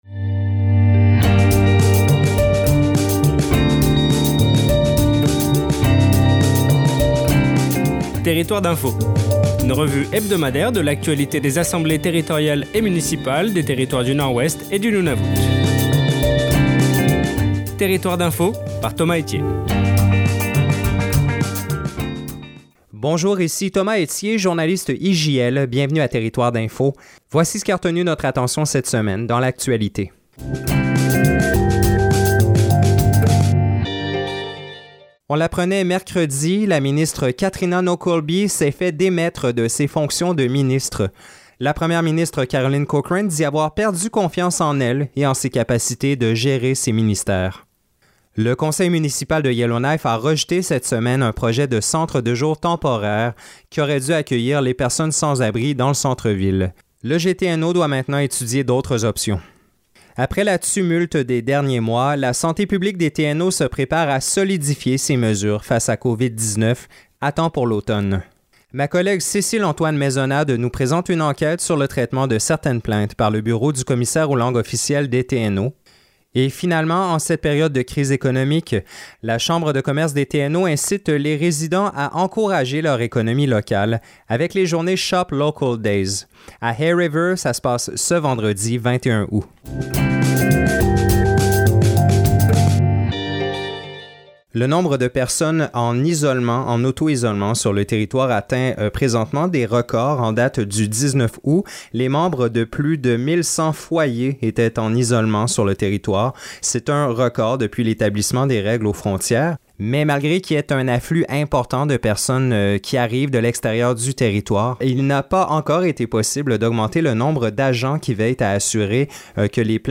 Le bulletin hebdomadaire Territoires d’info